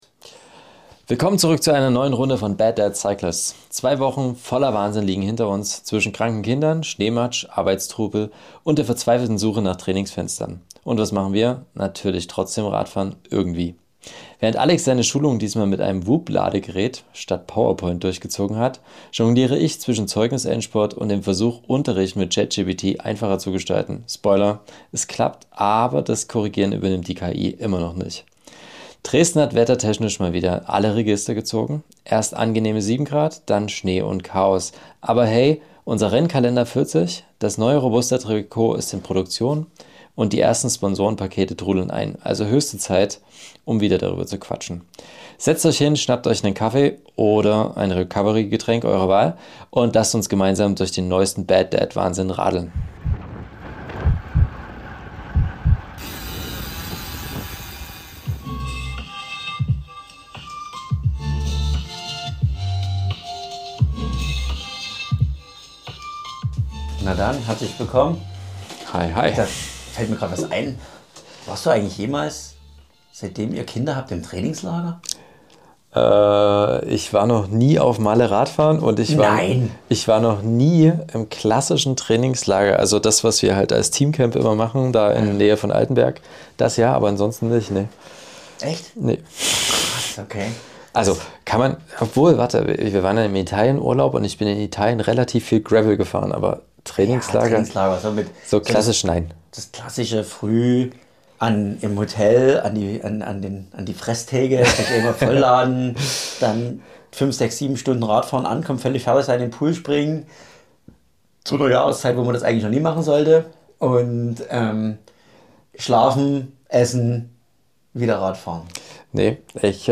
Zwei halbwissende Väter im Austausch über Alltag, Familie und Sport.